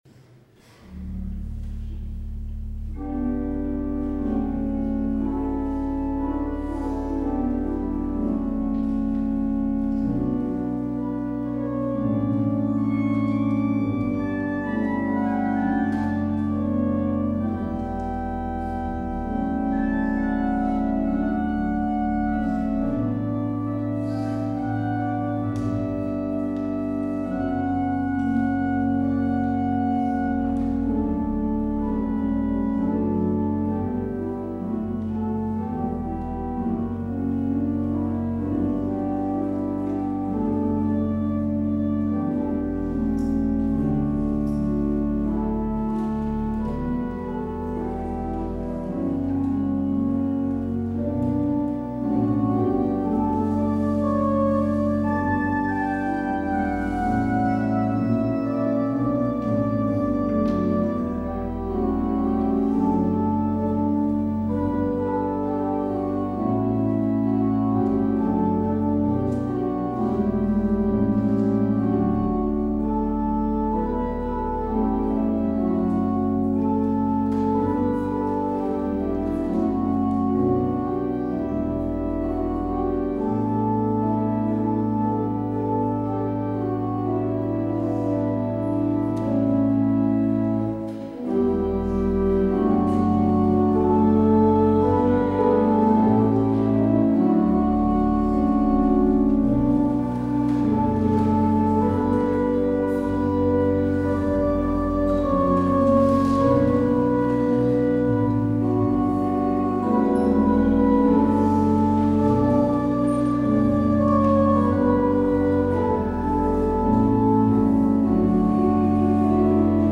 Luister deze kerkdienst hier terug: Alle-Dag-Kerk 23 januari 2024 Alle-Dag-Kerk https